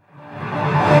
VEC3 Reverse FX
VEC3 FX Reverse 25.wav